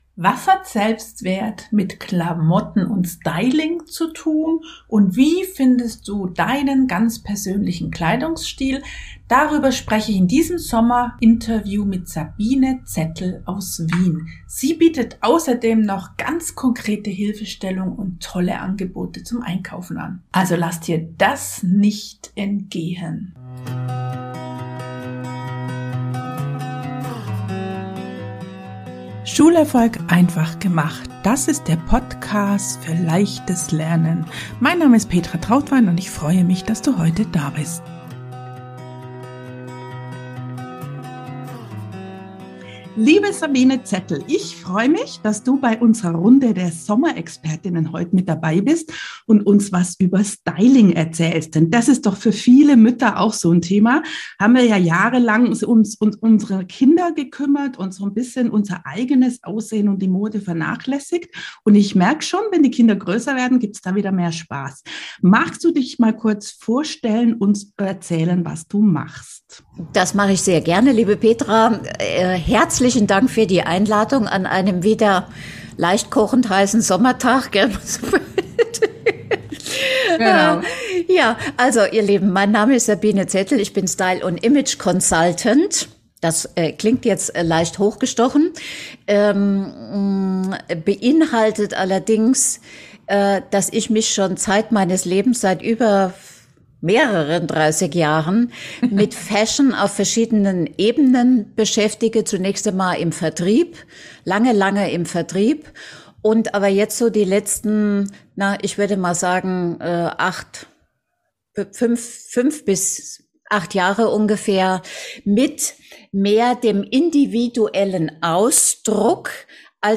Sommer Interview